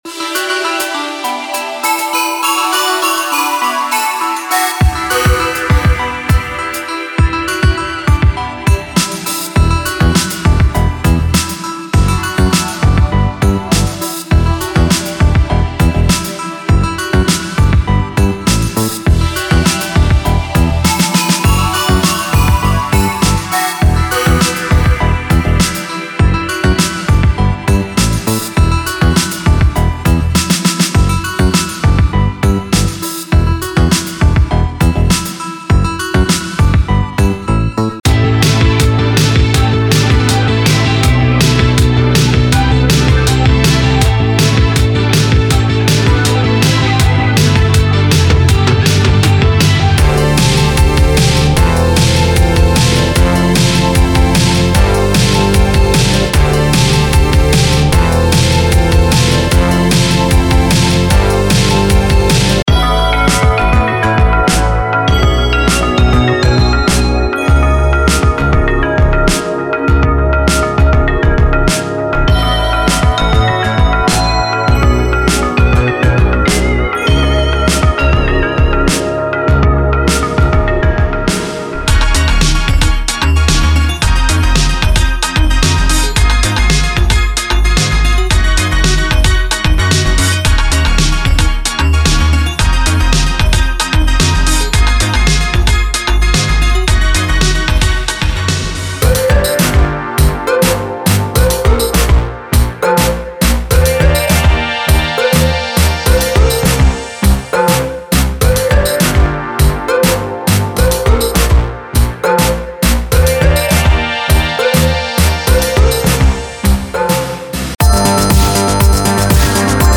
80s Synth Pop Ballads
1980s Style Loops
Synthwave Loops